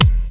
• Old School Acoustic Steel Kick Drum F Key 99.wav
Royality free steel kick drum sample tuned to the F note. Loudest frequency: 923Hz
old-school-acoustic-steel-kick-drum-f-key-99-bhR.wav